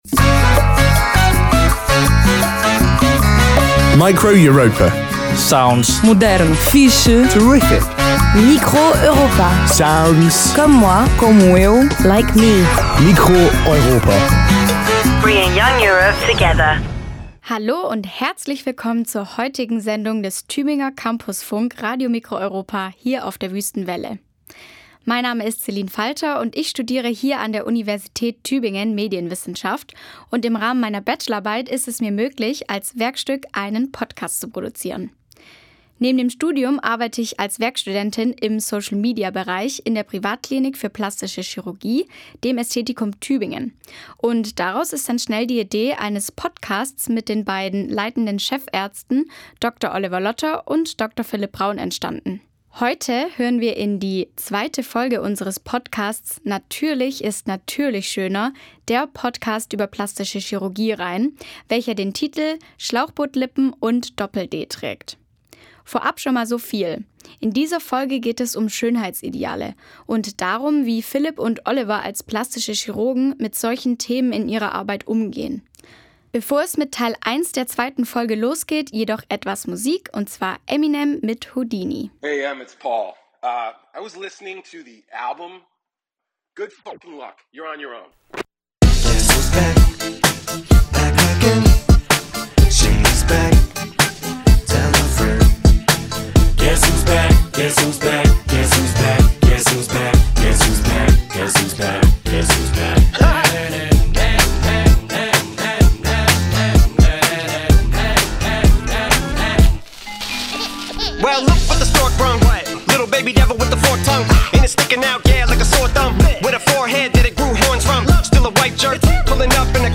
In fünf Folgen sprechen die drei ausführlich über Themen wie Schönheitsideale, Pfusch oder Hilfemaßnahmen in der Plastischen, Rekonstruktiven und Ästhetischen Chirurgie und hinterfragen dabei ständig die Verantwortung von Ärzten und Ärztinnen.